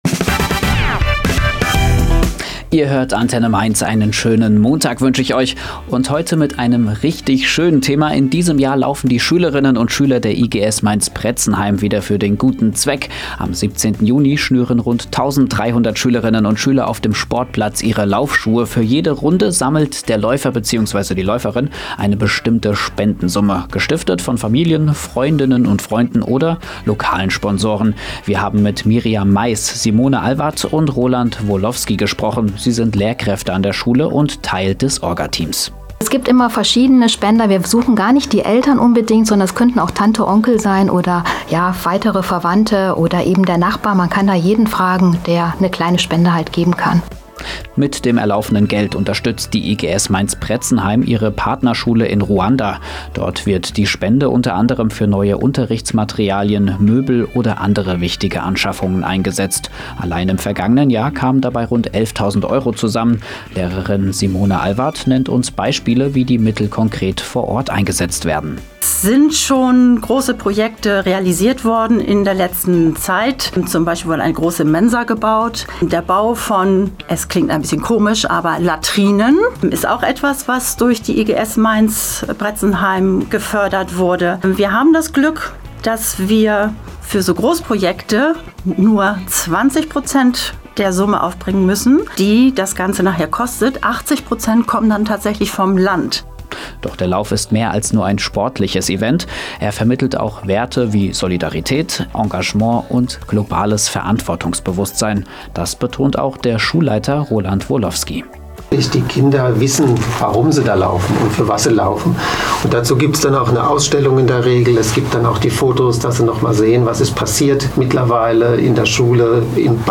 Bereits im Vorfeld hatte der Sender Antenne Mainz über die Aktion berichtet – den Radiobeitrag finden Sie hier: